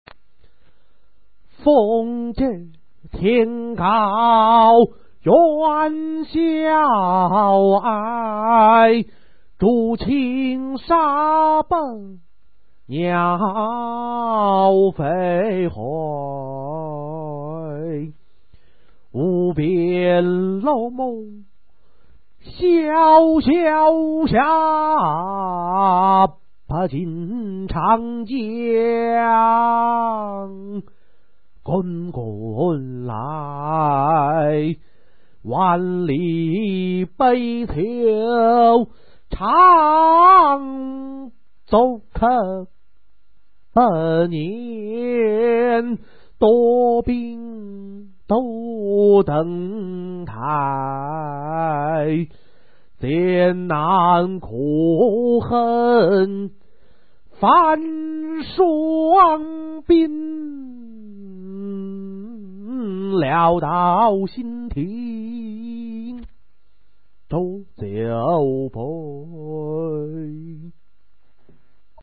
杜甫《登高》原文和译文（含赏析、吟唱）